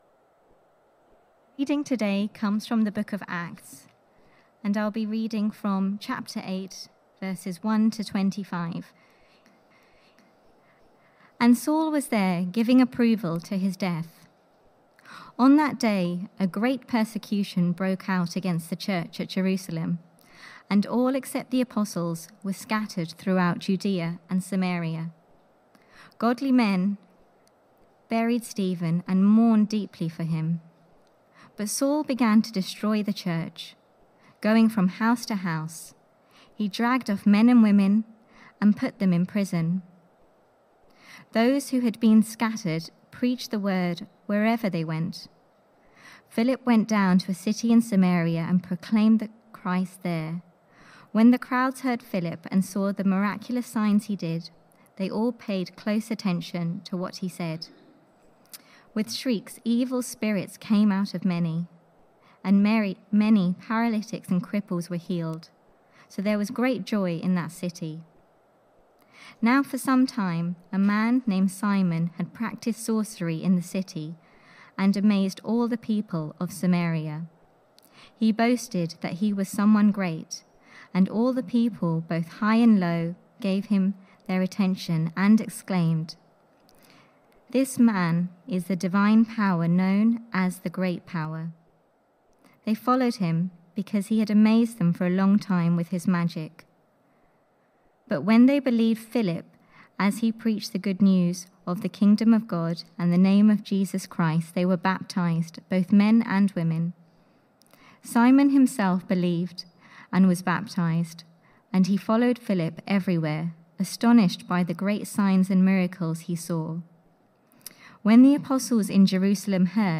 Theme: The church expands the boundaries Sermon